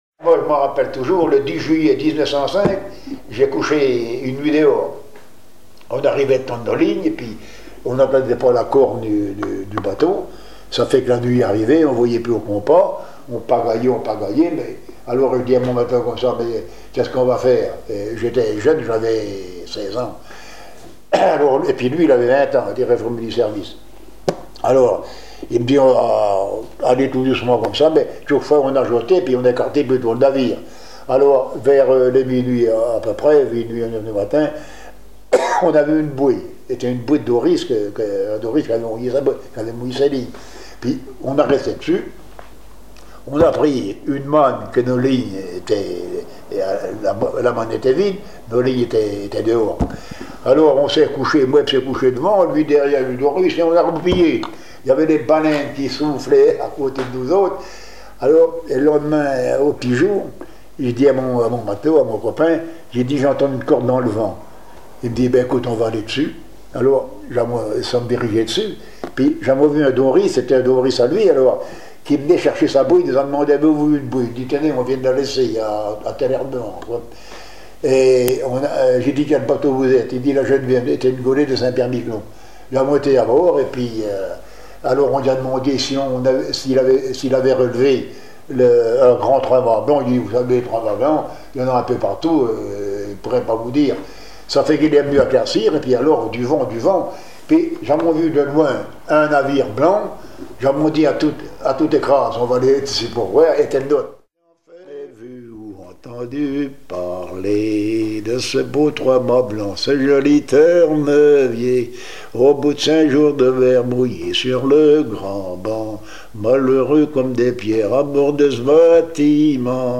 Saint-Pierre-en-Port
Chansons maritimes